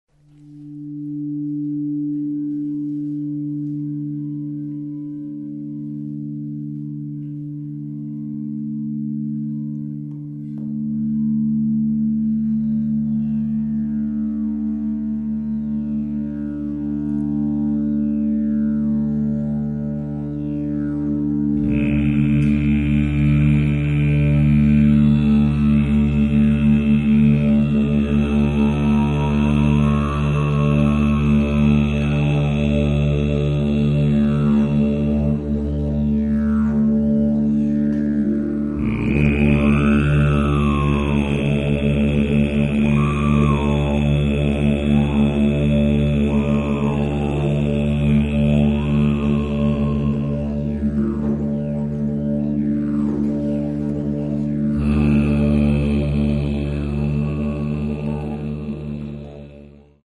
canto armónico y los diapasones.
Armonicos.mp3